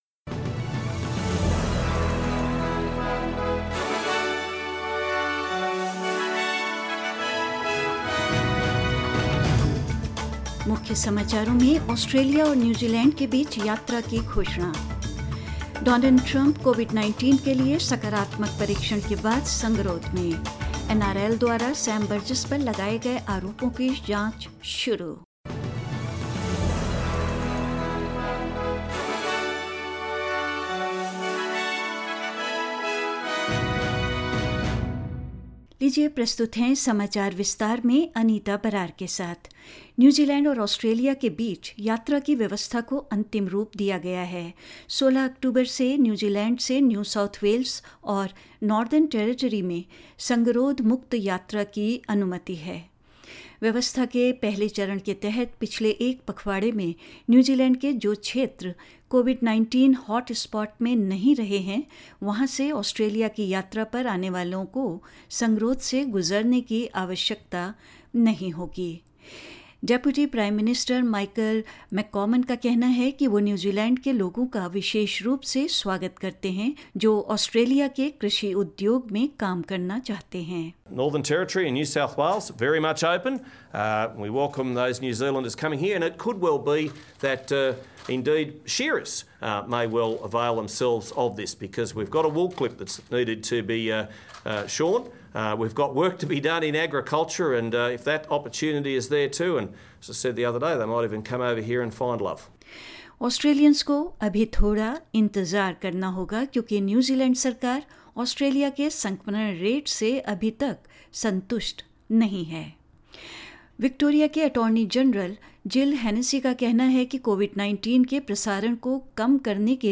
News in Hindi 2nd October 2020